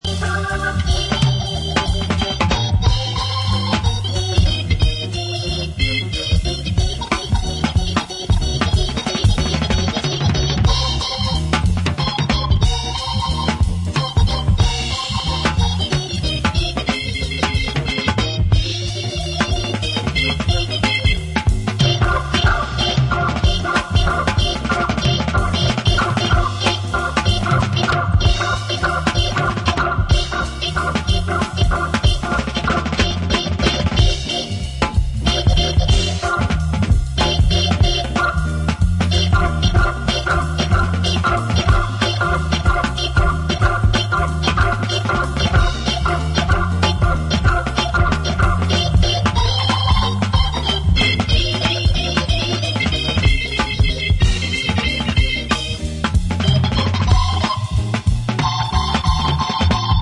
the stuttering organ and nearly free interludes
Soul